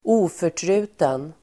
oförtruten adjektiv, untiring Uttal: [²'o:för_tru:ten]